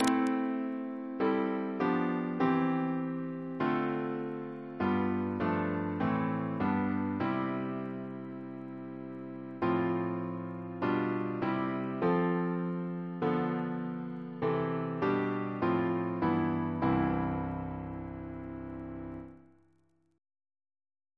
Double chant in C Composer